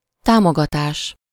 Ääntäminen
France: IPA: [su.tjɛ̃]